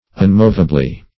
unmovably - definition of unmovably - synonyms, pronunciation, spelling from Free Dictionary Search Result for " unmovably" : The Collaborative International Dictionary of English v.0.48: Unmovably \Un*mov"a*bly\, adv.